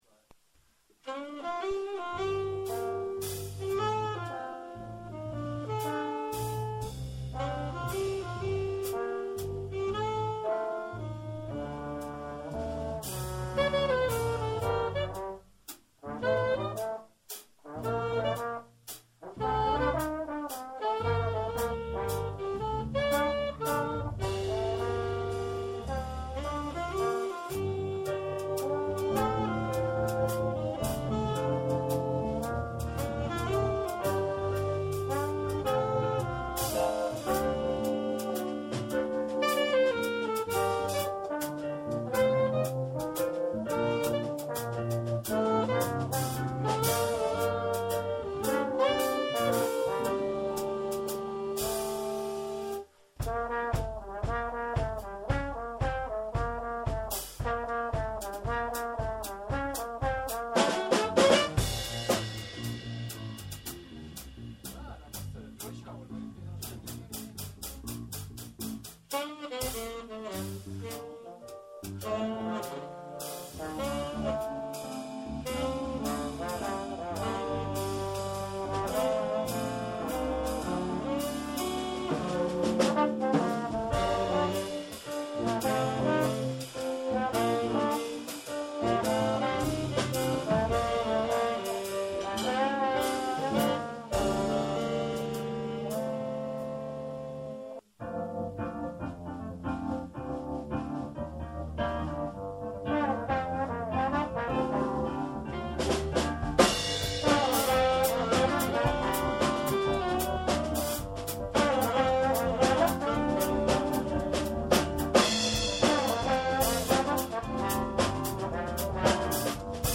· Genre (Stil): Jazz
· Kanal-Modus: stereo · Kommentar